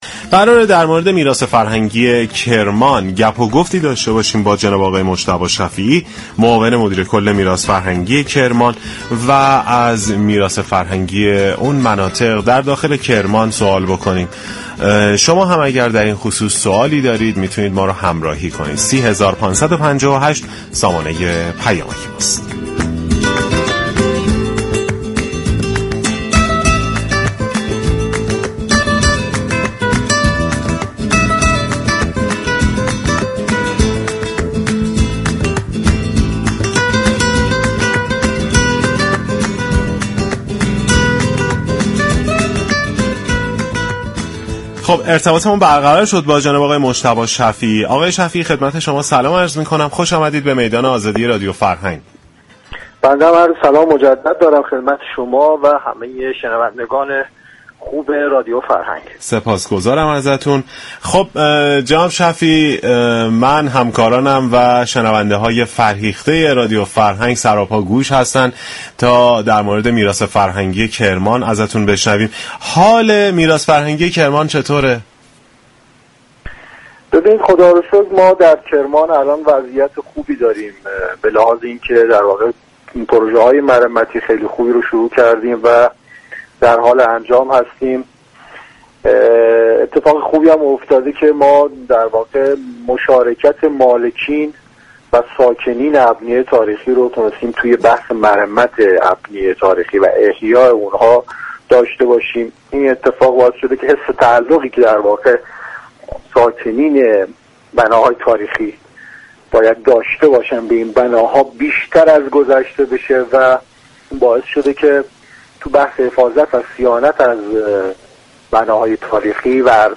در گفتگو با برنامه ی میدان آزادی